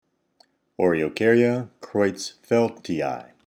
Pronunciation/Pronunciación:
O-re-o-cár-ya creutz-féld-ti-i